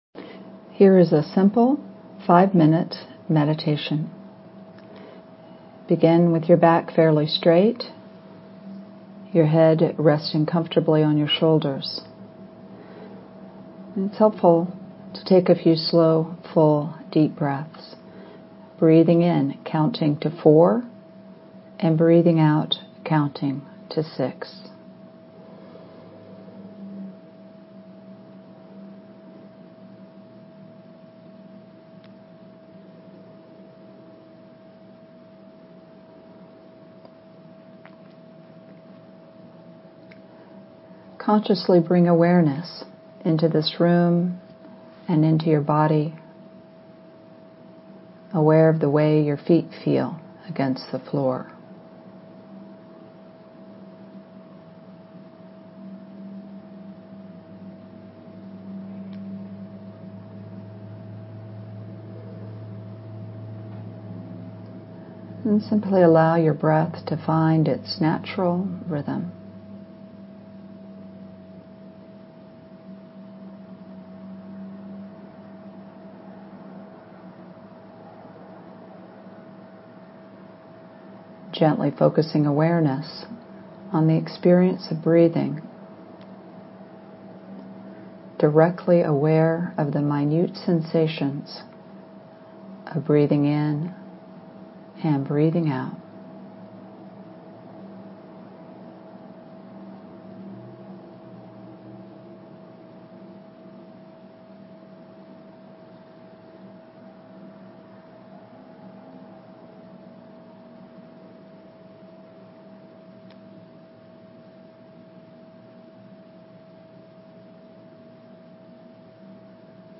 Daily Dose of Inner Peace: Guided Meditations
Here is an easy 5-minute meditation that includes instructions. Note that there will be periods of silence in the middle of the recording to allow you time to incorporate the instructions into a silent meditation.